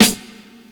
Medicated Snare 38.wav